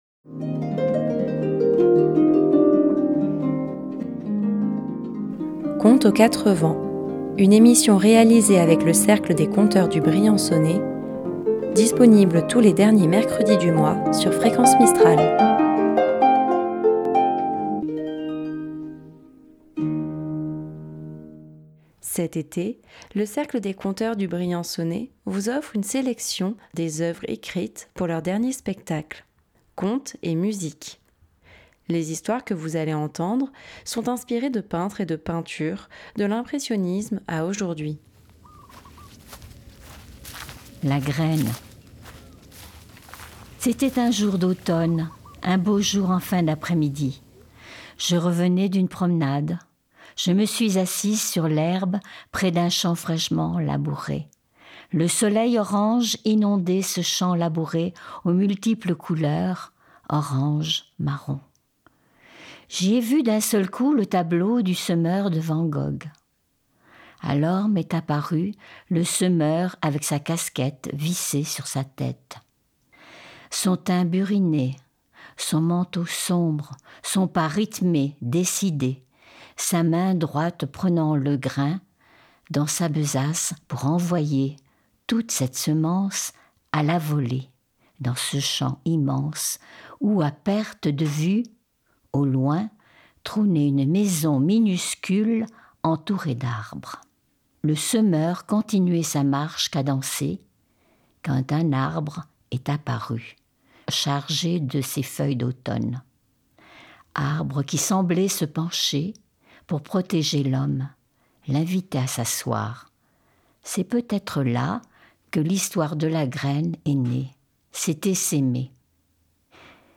Dans cet épisode, vous entendrez des contes écrits par les membres de l'association pour leur dernier spectacle : Contes et Musique. Des histoires inspirées de tableaux, de l'impressionisme à nos jours.